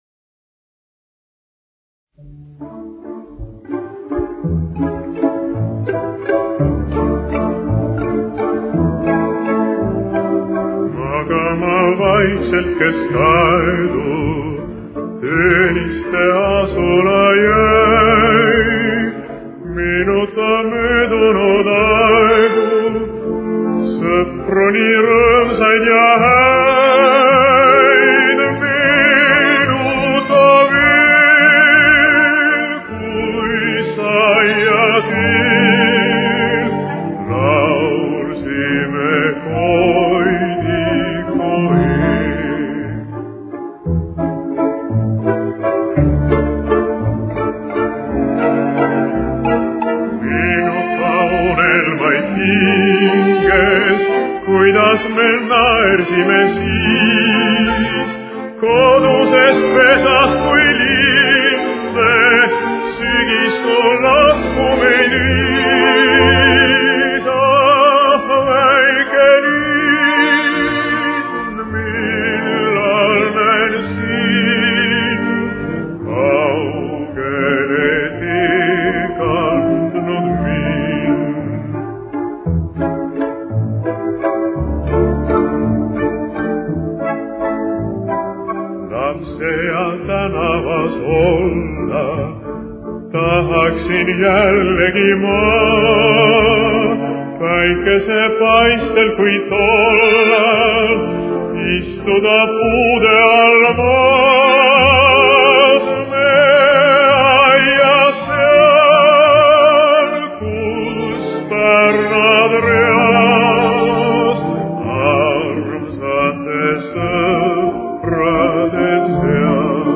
Ре минор. Темп: 170.